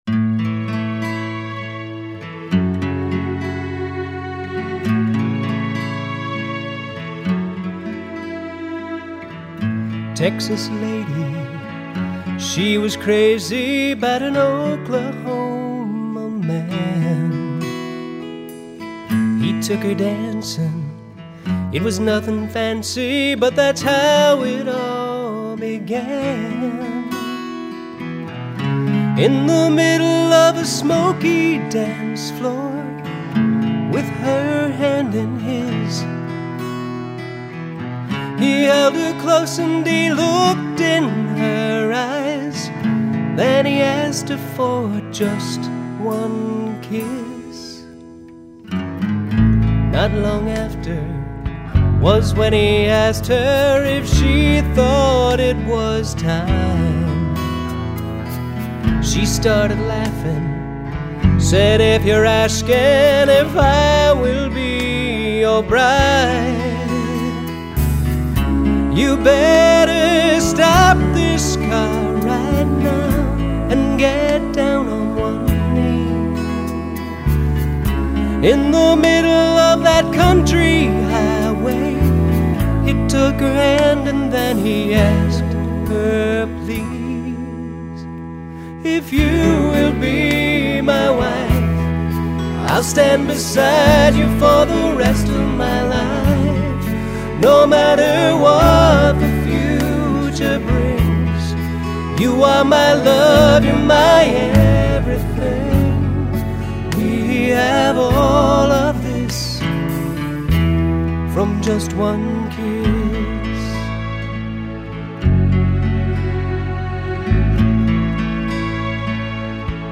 A Nice Story Song